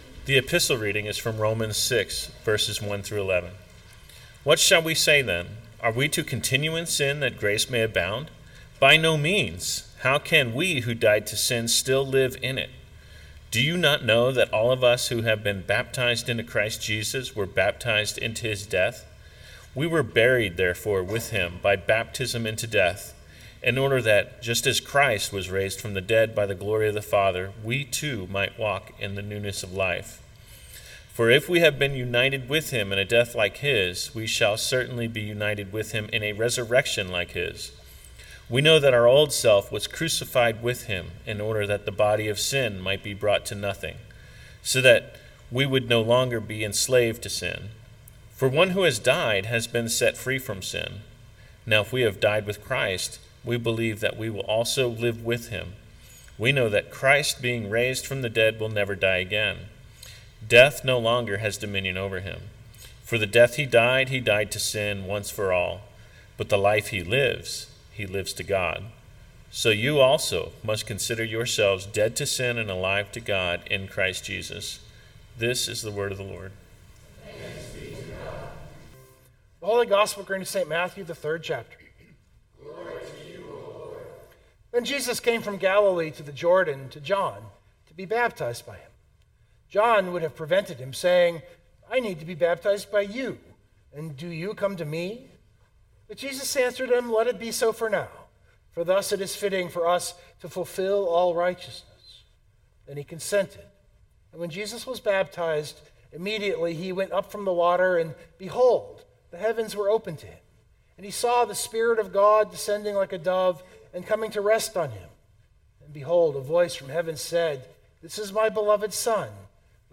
So it was the perfect confluence for something of a teaching sermon.